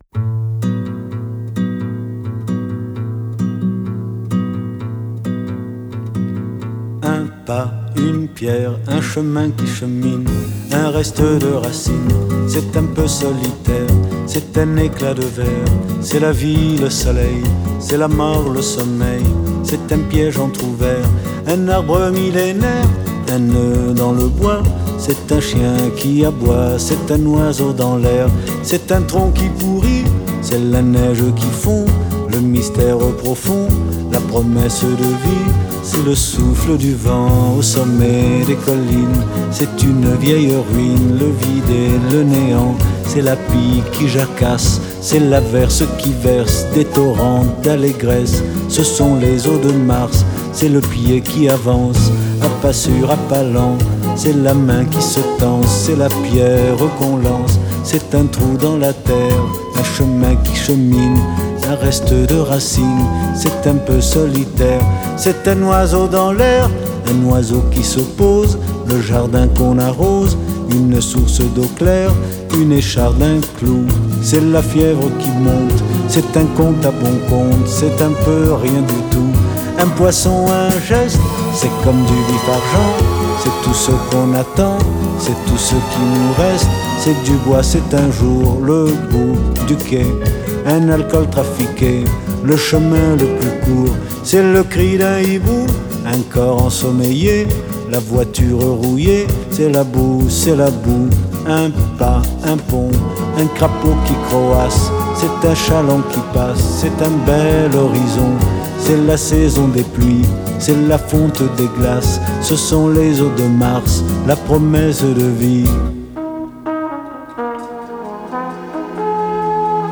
Ecole Elémentaire Publique Les Embruns de Saint-Quay-Portrieux La suite des morceaux pour la chorale des "grands"